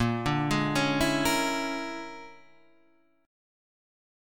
A# 7th Sharp 9th Flat 5th